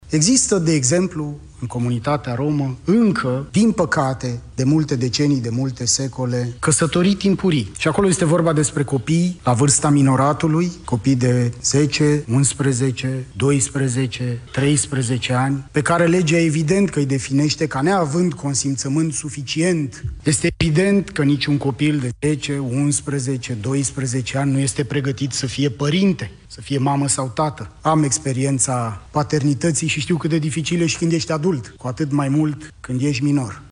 Situația a fost analizată într-o conferință găzduită de ministerul Muncii și Familiei, la care au participat și reprezentanții UNICEF și ONU.
Marius Lazurca: „Unele forme de pedeapsă corporală nici măcar nu sunt percepute drept violență, ci în mod greșit ca măsuri disciplinare”